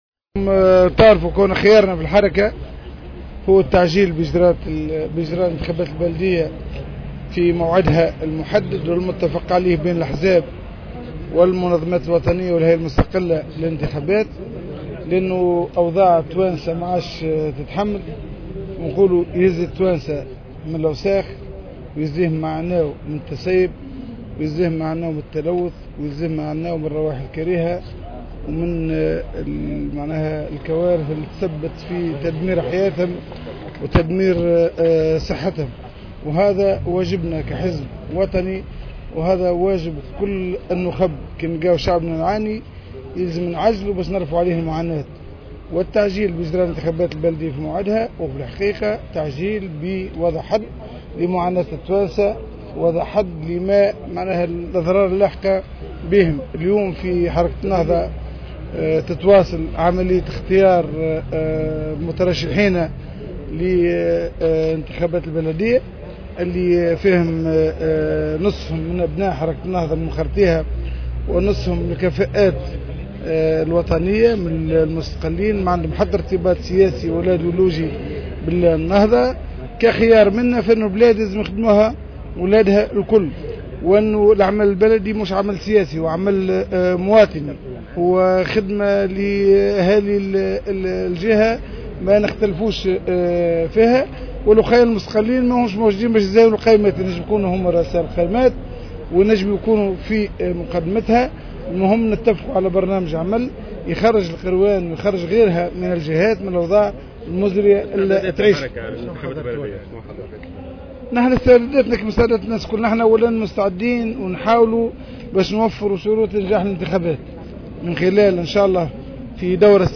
وأكد البحيري في تصريح لمراسل الجوهرة أف أم، أن حركة النهضة تواصل اختيار المترشحين لقائماتها الانتخابية والتي ستخصص نصفها لأبناء الحركة، فيما سيتم اختيار النصف الثاني من الكفاءات الوطنية غير المتحزبة، على اعتبار وأن العمل البلدي ليس عملا سياسيا بقدر ماهو "مواطني"، حسب تعبيره.